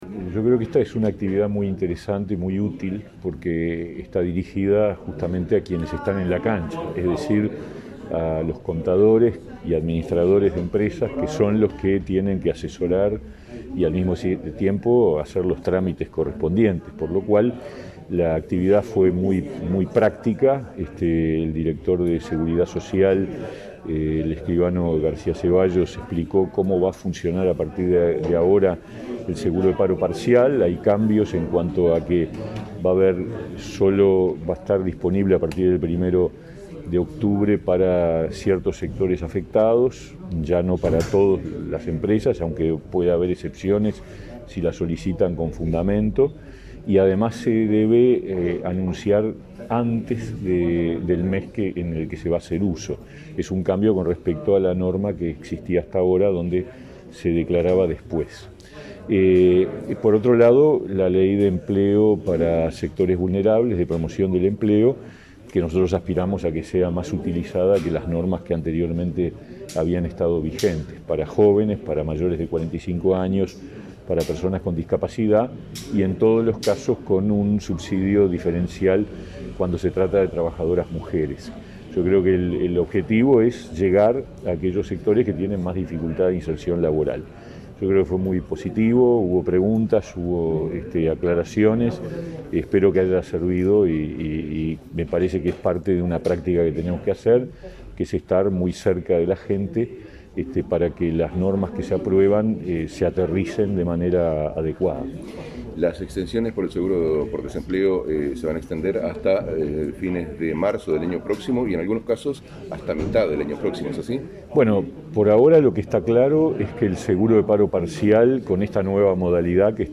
Declaraciones del ministro de Trabajo y Seguridad Social, Pablo Mieres, a la prensa
Declaraciones del ministro de Trabajo y Seguridad Social, Pablo Mieres, a la prensa 22/09/2021 Compartir Facebook X Copiar enlace WhatsApp LinkedIn Tras participar en un evento organizado por el Colegio de Contadores, Economistas y Administradores del Uruguay, Mieres efectuó declaraciones a la prensa. Informó que regirán modificaciones en el seguro de paro parcial para sectores afectados por la pandemia.